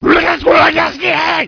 zombie_scream_6.wav